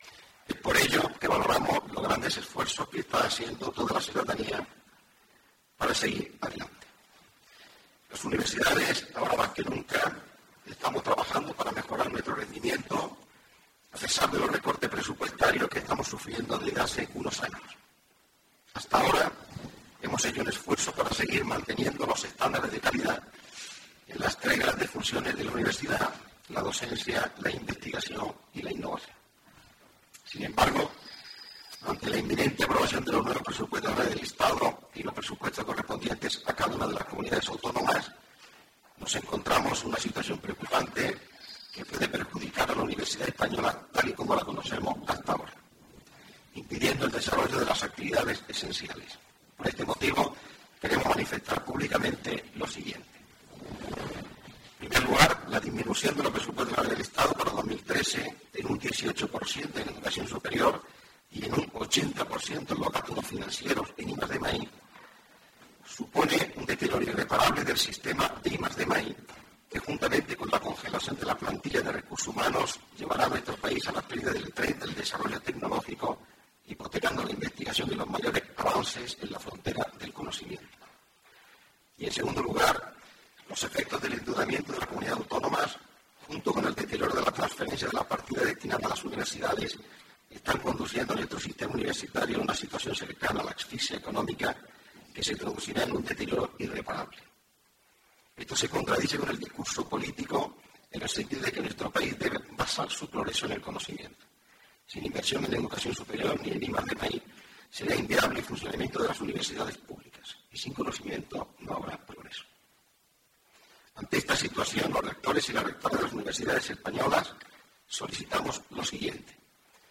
El Rector de la UJA lee el comunicado de la CRUE
El Rector de la Universidad de Jaén, Manuel Parras, leyó un comunicado -de manera simultánea con el resto de rectores de las universidades españolas-, en el que da a conocer a la sociedad la preocupación por los recortes en los Presupuestos Generales del Estado, en materia de Educación Superior y de I+D+i.